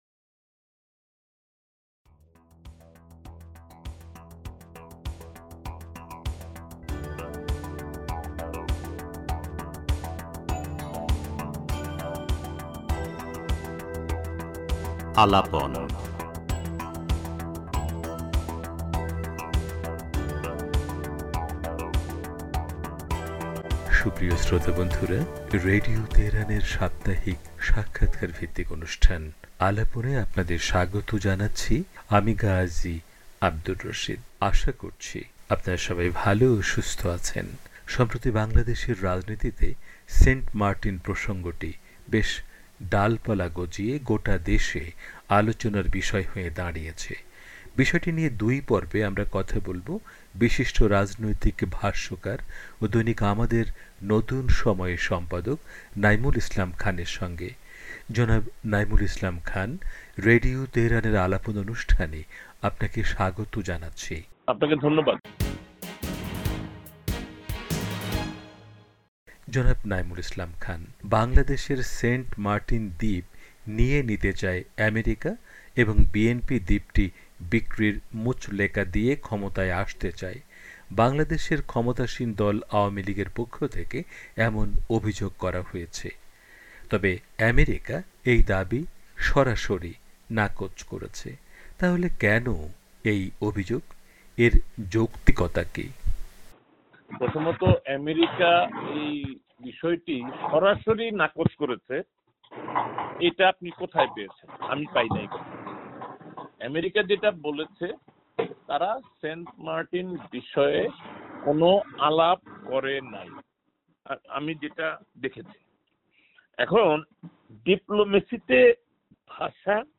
সাক্ষাৎকারের প্রথম পর্ব পুরোটাই তুলে ধরা হলো।